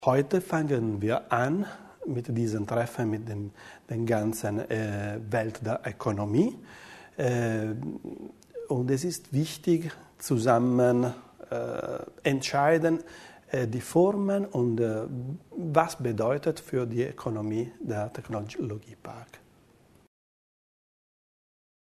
Der Landesrat über den Ausgang des Treffens mit den Wirtschaftsvertretern